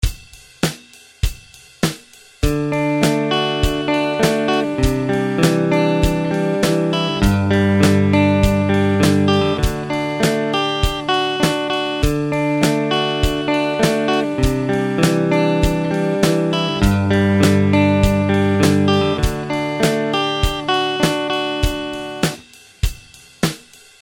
Open Chord Arpeggios
D C G Open Chord Arpeggios | Download